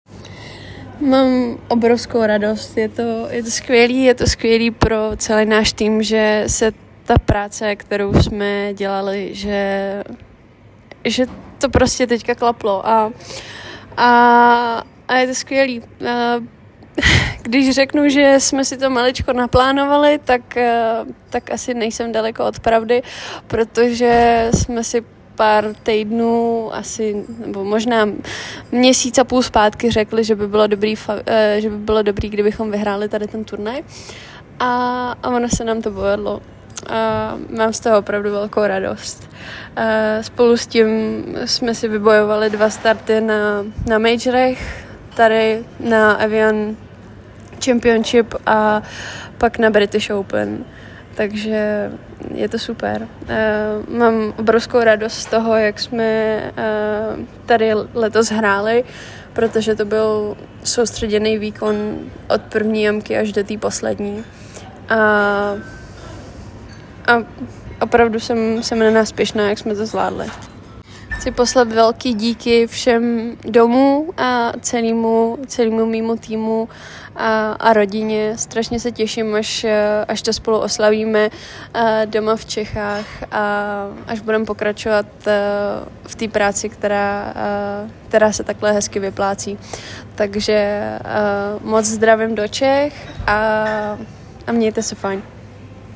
Audio reakce